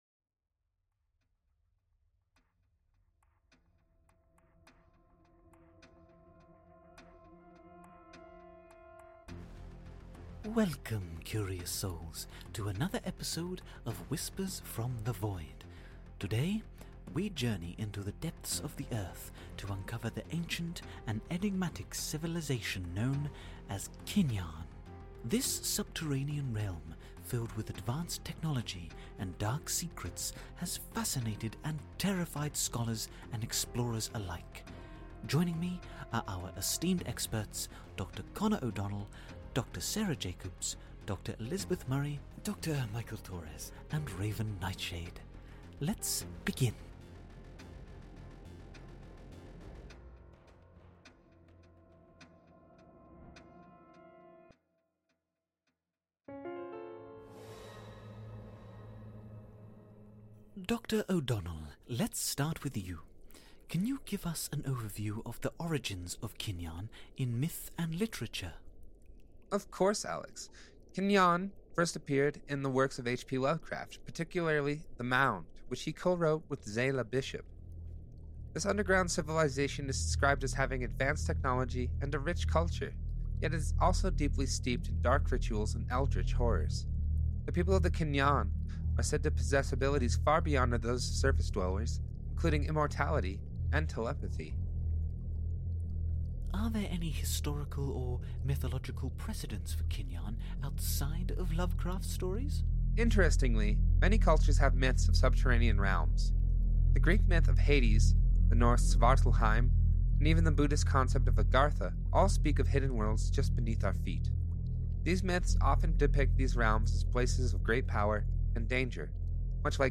Play Rate Listened List Bookmark Get this podcast via API From The Podcast Whispers from the Void is an immersive audio drama that plunges listeners into a world where the boundaries between reality and the supernatural are blurred.